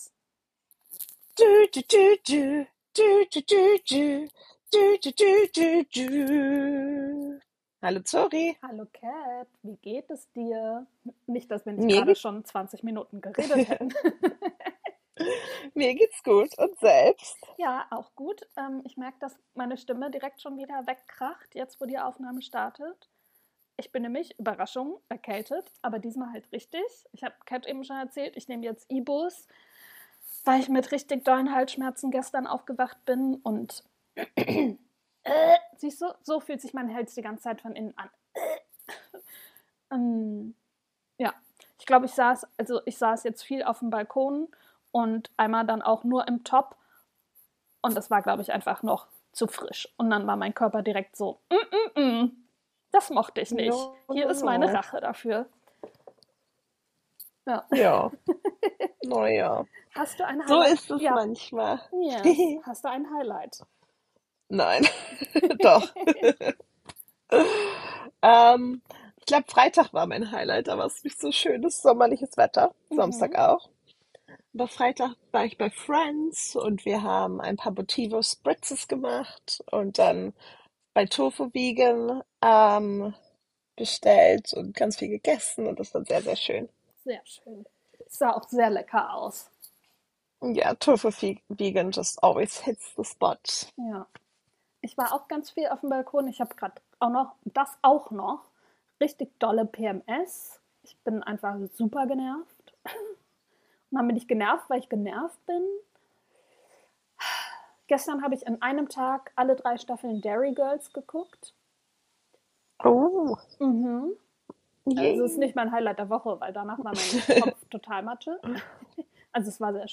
Zwei beste Freundinnen – getrennt durch London und Düsseldorf, aber immer connected! In diesem Podcast plaudern wir über alles, was uns gerade bewegt: Dating-Drama, Girlpower, unser Chaos im Job, süße Katzenmomente, Serien-Binges und warum wir Die Sims lieben.
Locker, frech und authentisch – wie ein FaceTime-Call mit deiner BFF, nur mit noch mehr Tea und Hot Takes.